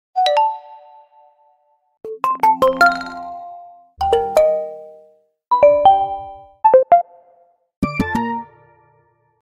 📱🔔✨ Evolution of Oppo Notification sound effects free download
📱🔔✨ Evolution of Oppo Notification Sounds explores the progression of notification tones on Oppo devices, showcasing the brand's unique sound design and how it has evolved over time to enhance user experience.